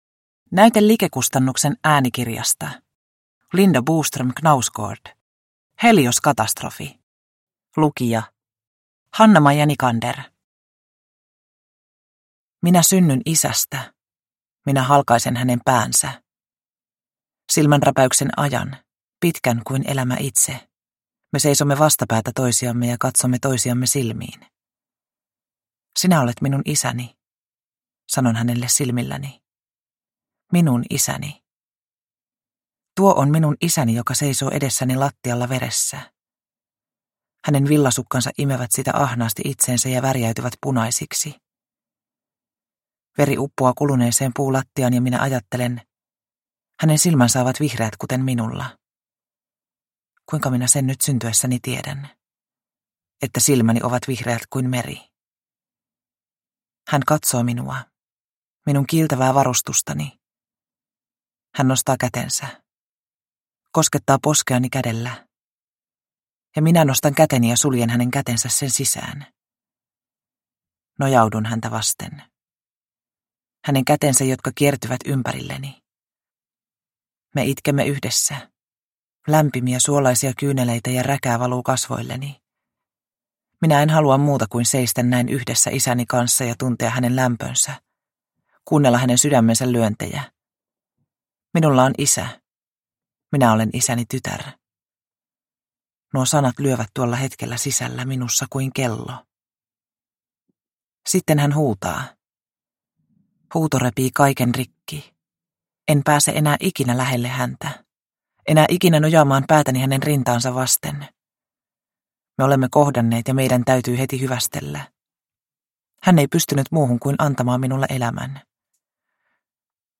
Helioskatastrofi – Ljudbok – Laddas ner